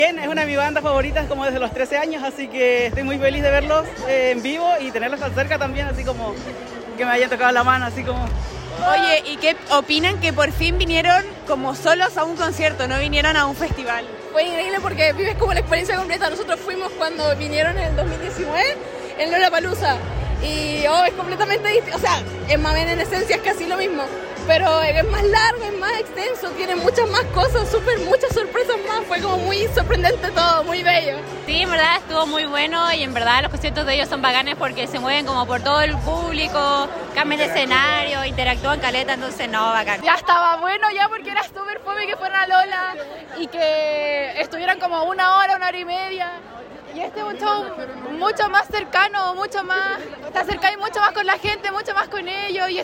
Por supuesto que estuvimos en el concierto para conversar con sus fanáticos y nos contaron cómo vivieron esta experiencia.
cu-top-fanaticos.mp3